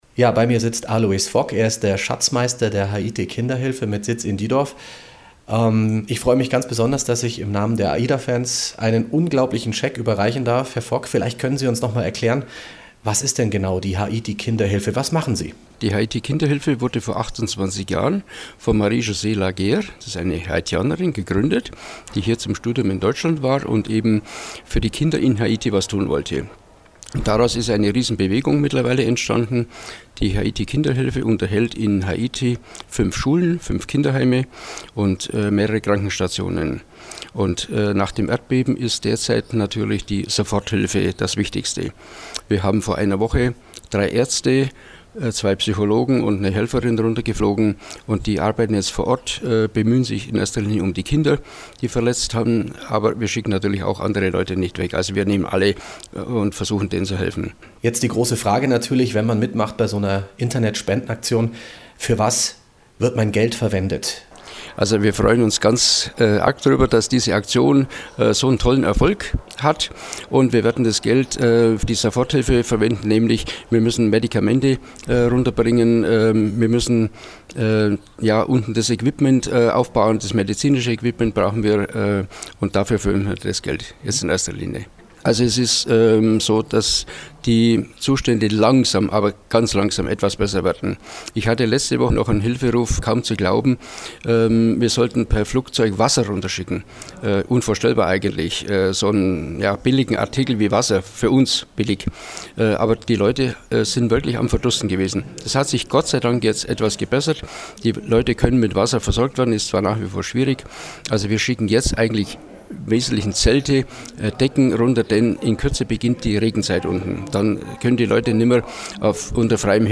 540-haiti-kinderhilfe-interview-mp3